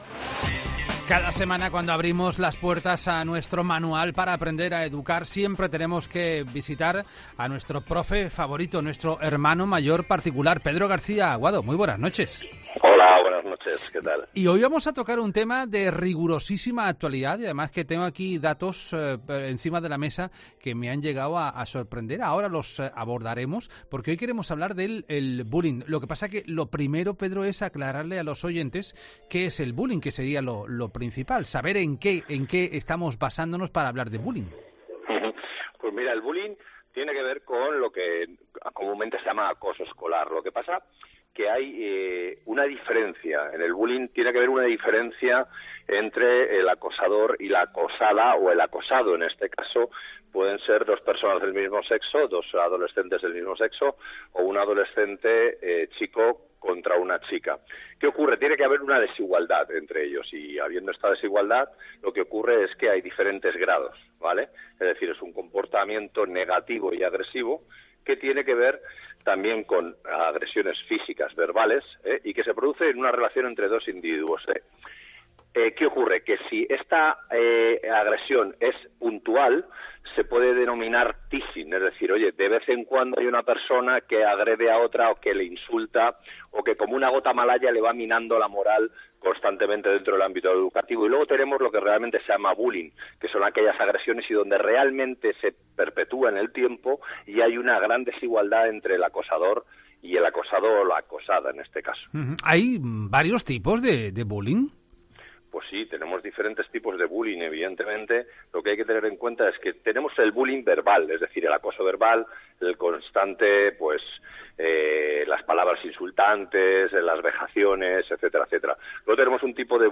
El intervencionista familiar Pedro García Aguado nos explica en 'La Noche de COPE' cómo podemos hacer frente al acoso